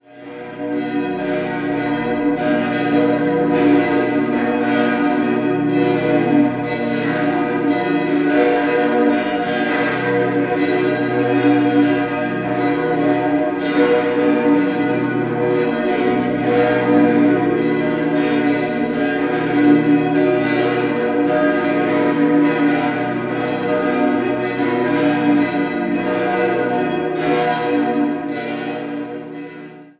5-stimmiges Geläut: h°-dis'-fis'-h'-dis'' Die Glocken 4-1 wurden 1867 (Nr. 1) und 1861 (alle 2-4) von der Gießerei Jakob Keller in Zürich-Unterstrass gegossen.